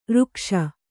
♪ řkṣa